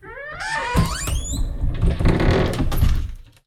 wardrobe2.ogg